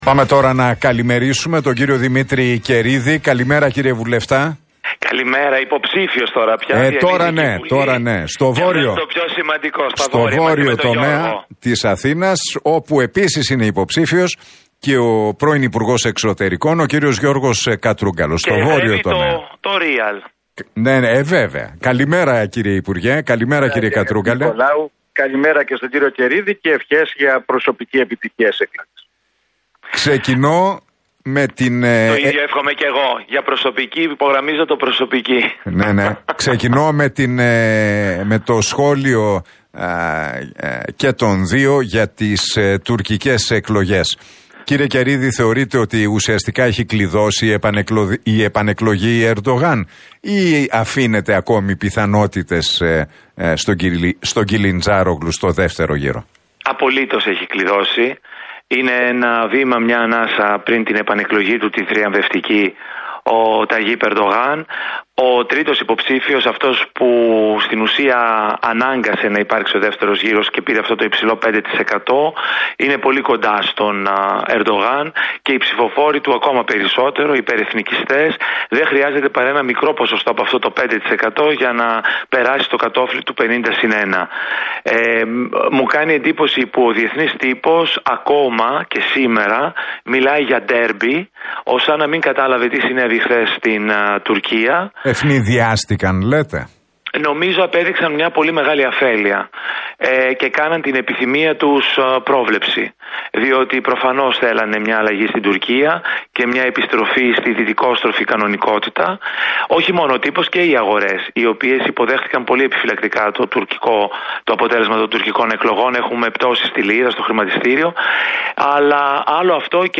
Τα ξίφη τους διασταύρωσαν στον αέρα του Realfm 97,8 και την εκπομπή του Νίκου Χατζηνικολάου, σε ένα debate ο υποψήφιος με τη ΝΔ, Δημήτρης Καιρίδης και ο υποψήφιος με τον ΣΥΡΙΖΑ, Γιώργος Κατρούγκαλος.